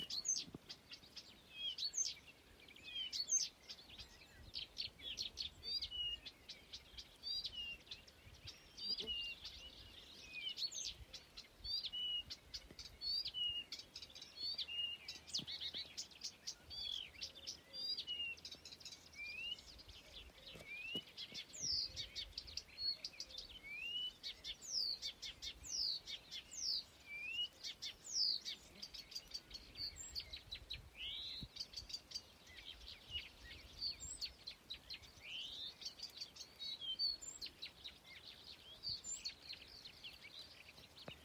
Blyth’s Reed Warbler, Acrocephalus dumetorum
StatusSinging male in breeding season
NotesDzied lauku ceļmalas krūmos.